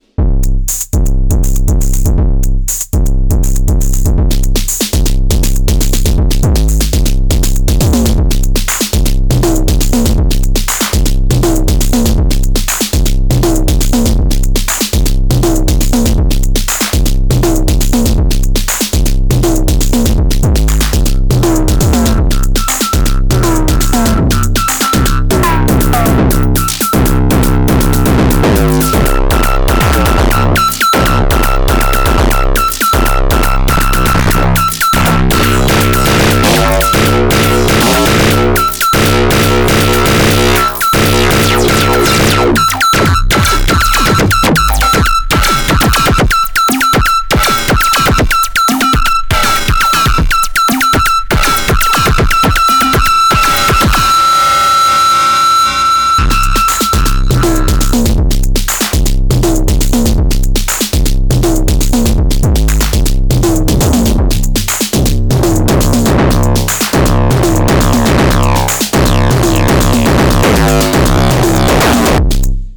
(Kick taken from HyperVault - New Soundpack for Hyper Pop, IDM )